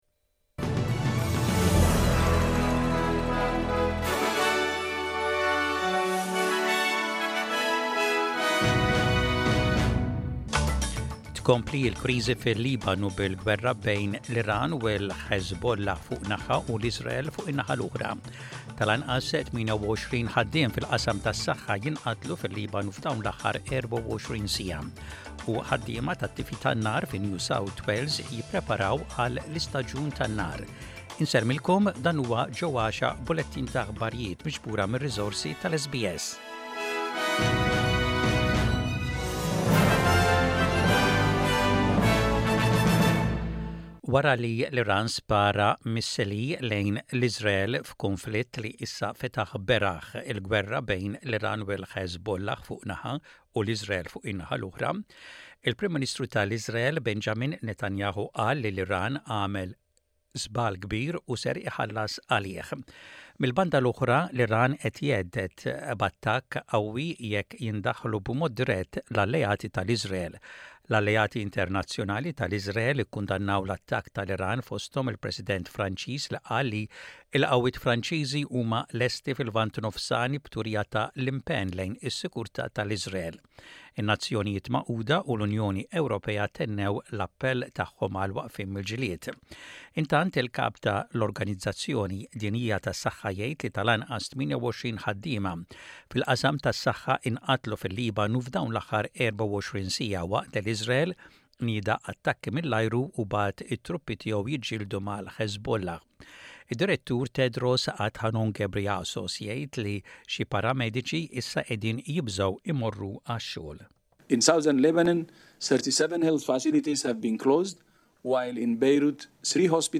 SBS Radio | Aħbarijiet bil-Malti: 04.10.24